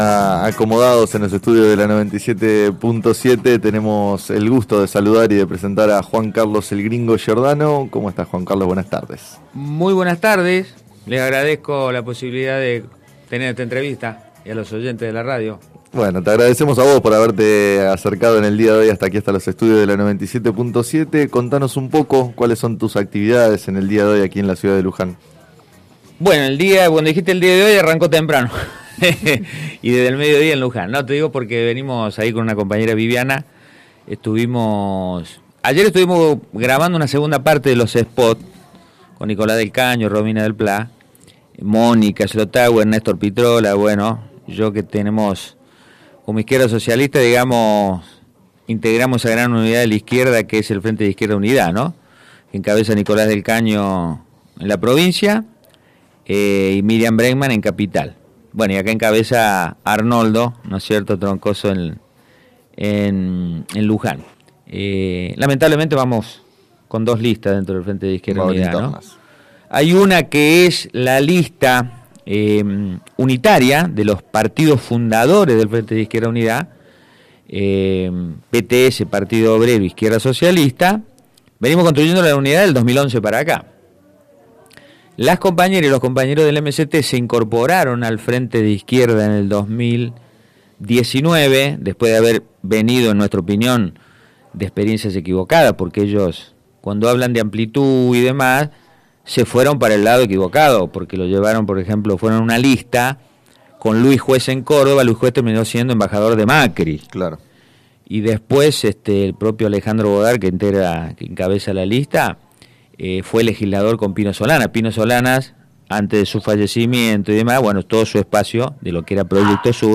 Acompañó a la presentación el diputado nacional Juan Carlos Giordano, precandidato a renovar su banca, quien en declaraciones al programa “Sobre las cartas la mesa” de FM Líder 97.7 consideró que existen sectores que votaron al Frente de Todos que están desencantados por su gestión.